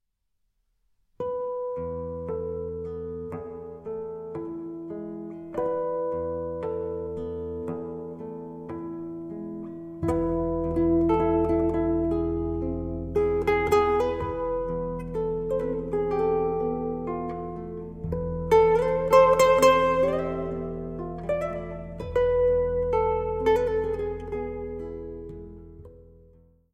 Tenor
Gitarre